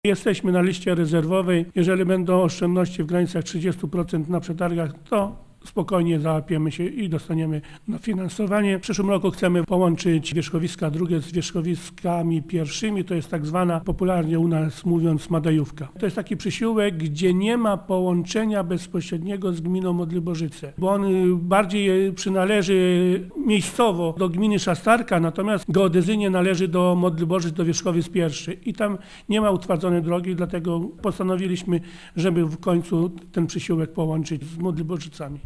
- Teraz też szanse nie są małe - uważa burmistrz Witold Kowalik: